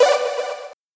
Computer Arp (2).wav